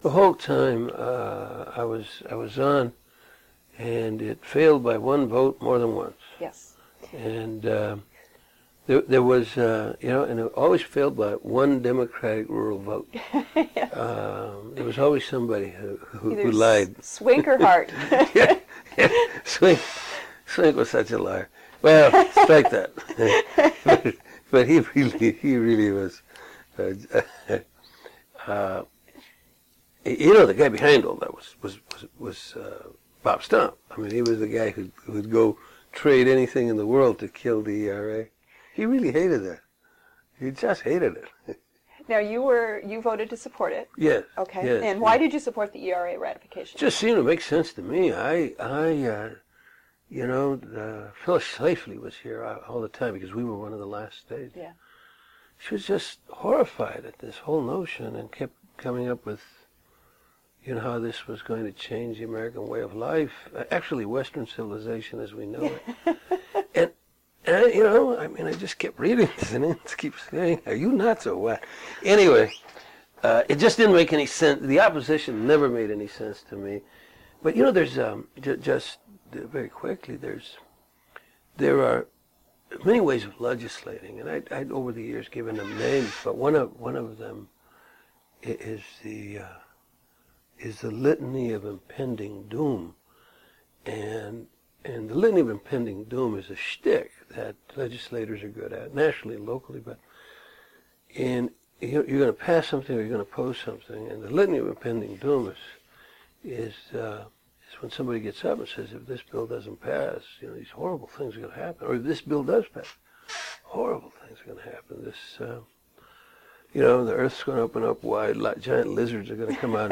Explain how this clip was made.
3 minutes of an original 90 minute sound cassette converted to MP3 (3.2 MB)